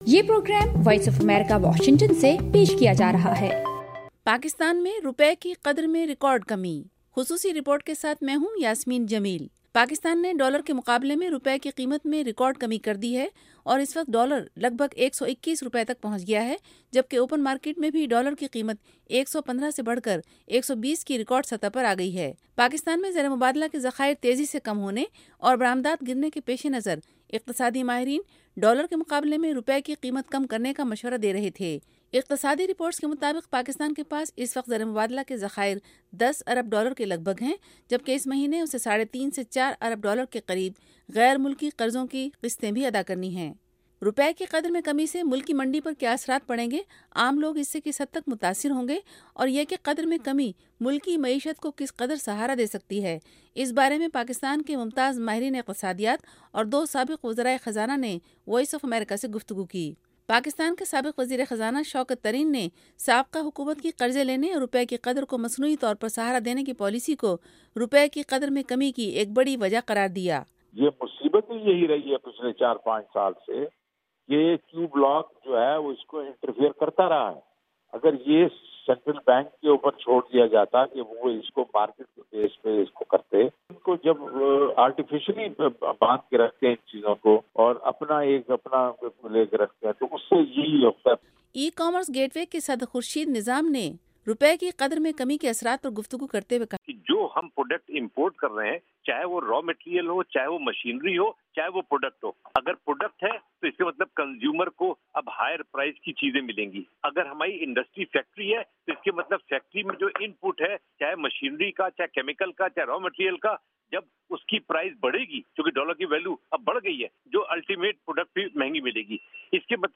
روپے کی قدر میں کمی سے ملکی منڈی پر کیا اثرات پڑیں گے، عام لوگ اس سے کس حد تک متاثر ہوں گے اور یہ کہ قدر میں کمی ملکی معیشت کو کس قدر سہارا دے سکتی ہے اس بارے میں پاکستان کے ممتاز ماہرین اقتصادیات اور دو سابق وزرائے خزانہ نے وائس آف امریکہ سے گفتگو کی۔